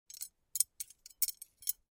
На этой странице собраны звуки вязания: мягкое постукивание спиц, шелест пряжи, ритмичные движения рук.
Звук вязания при соприкосновении спиц